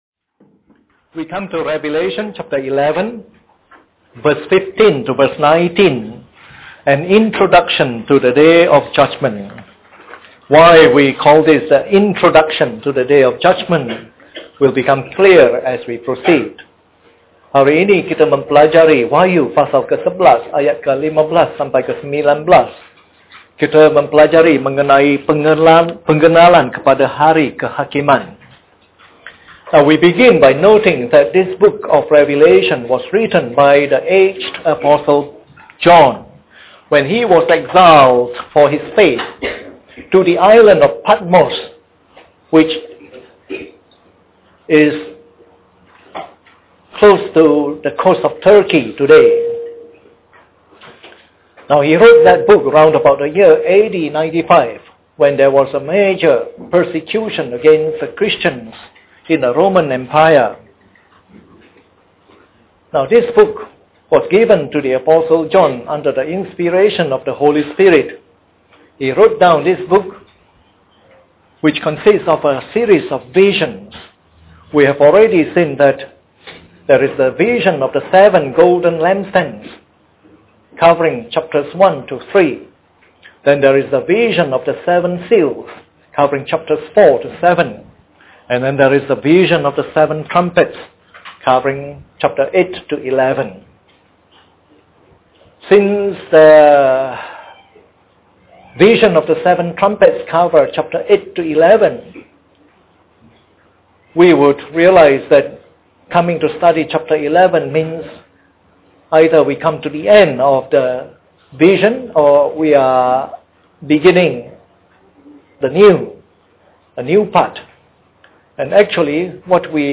Preached on the 16th of March 2008. This is part of the morning service series on “Revelation”.